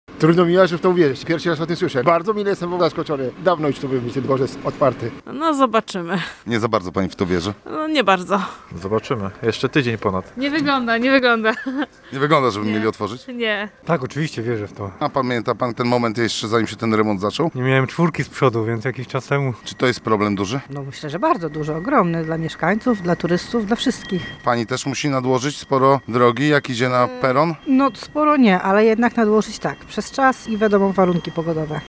Pasażerowie w to, że Dworzec Podmiejski zostanie otwarty już za tydzień, nie do końca wierzą: